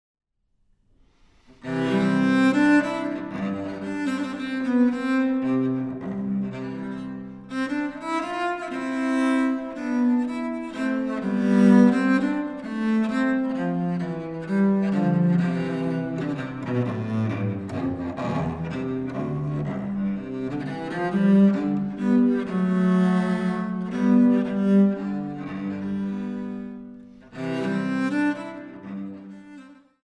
Courante (Ebenthal, Goess A)